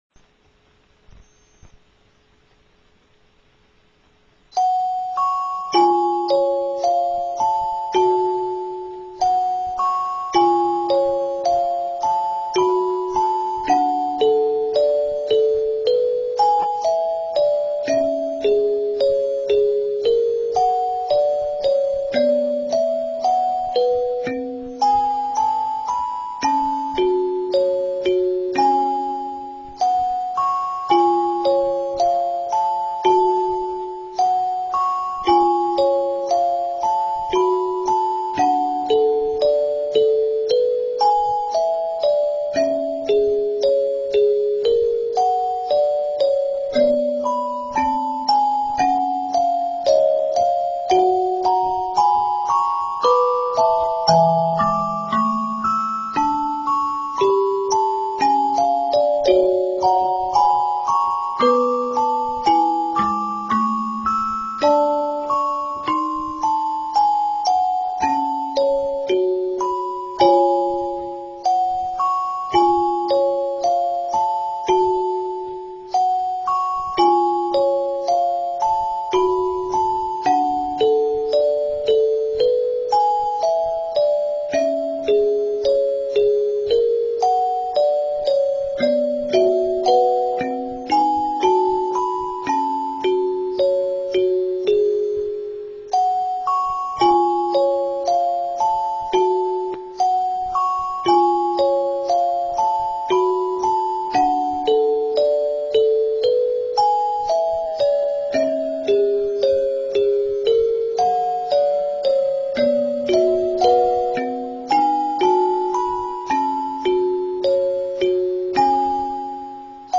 Music Box